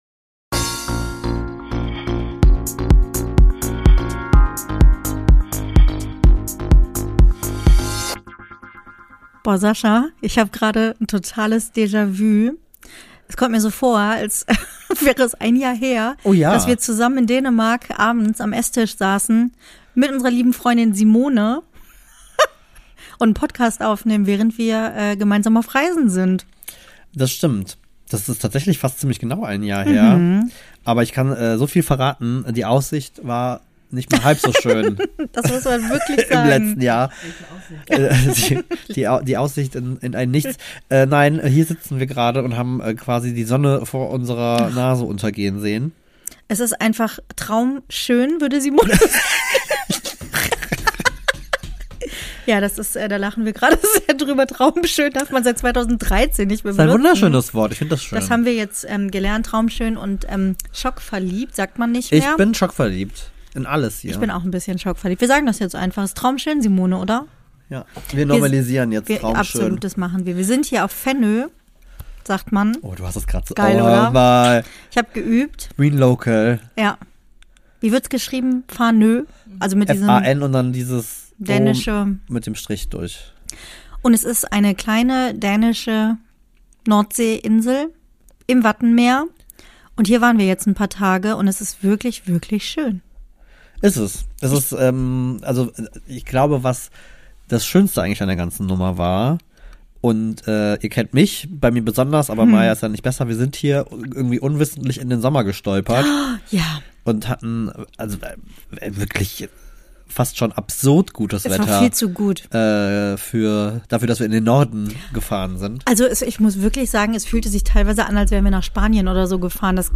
Beschreibung vor 1 Jahr Willkommen in Dänemark Wieder sitzen wir am Küchentisch, doch diesmal in unserem Ferienhaus in den Dünen auf der Insel Fanø.